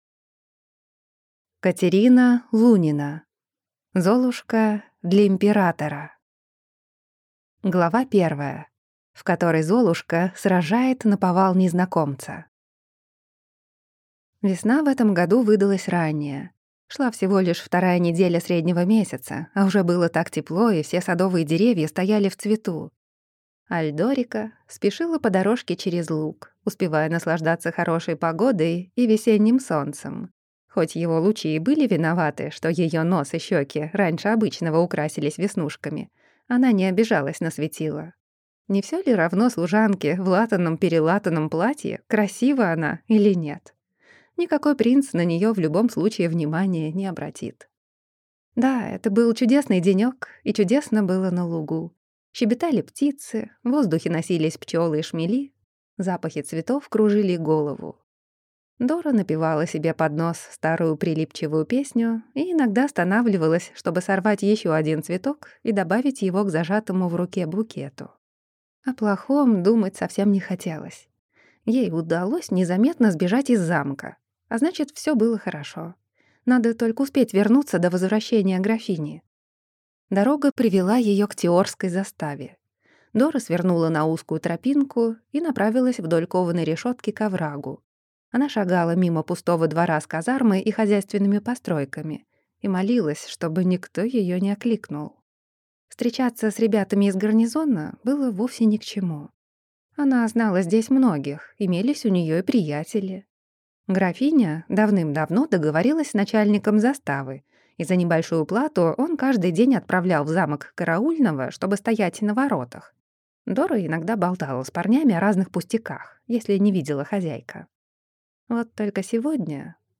Аудиокнига Золушка для императора | Библиотека аудиокниг